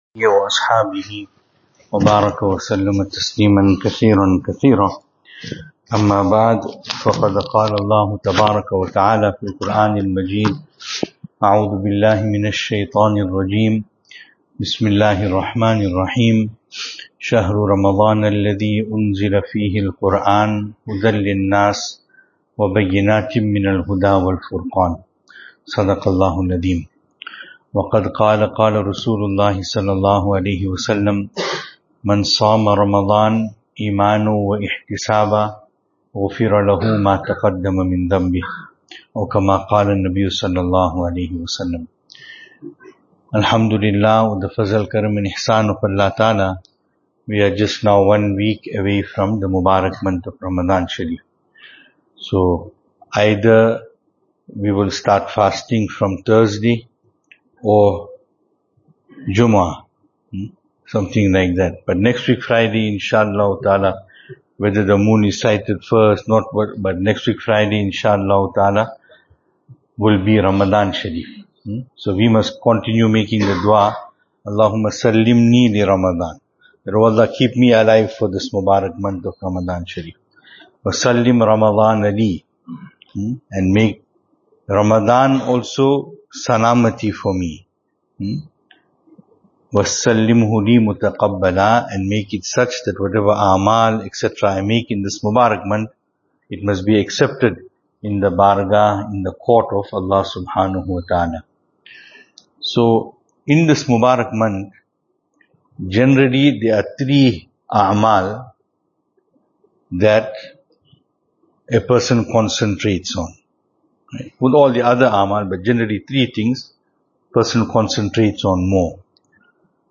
Jumuah Bayaan
2026-02-13 Jumuah Bayaan Venue: Albert Falls , Madressa Isha'atul Haq Series: JUMUAH Service Type: Jumu'ah Summary: ▪ Never speak anything negative of Ramadaan Shareef.